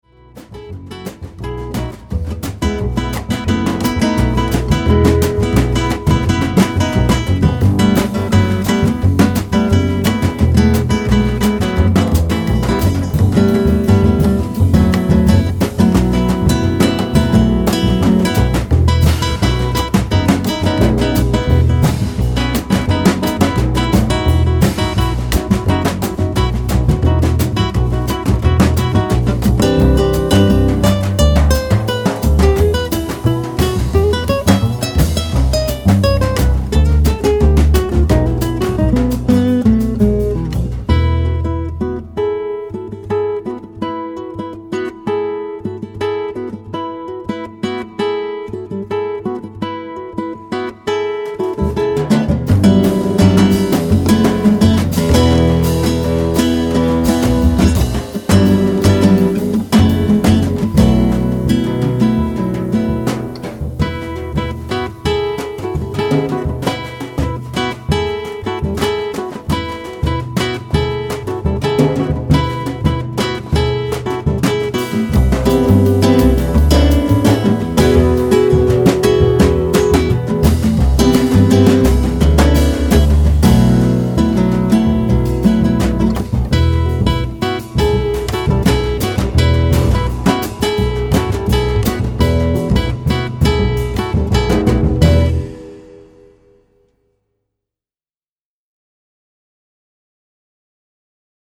contrabbasso
chitarra acustica
percussioni
Registrato in presa diretta